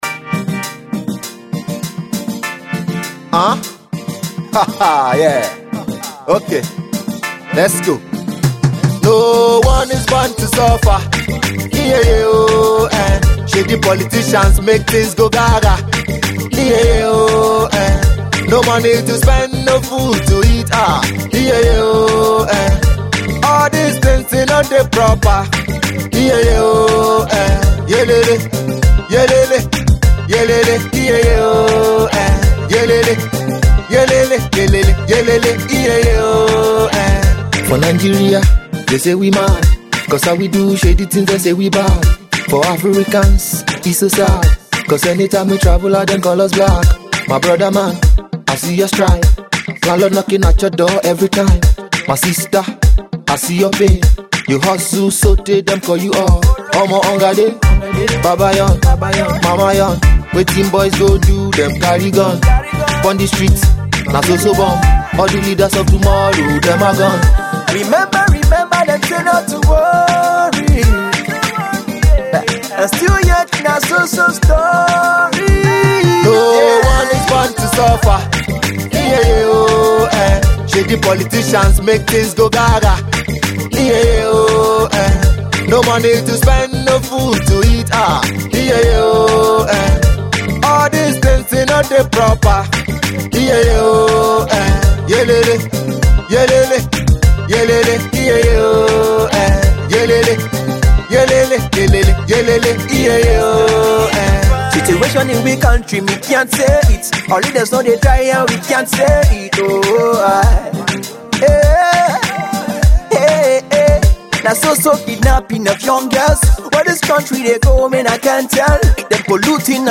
Afro Beat Tune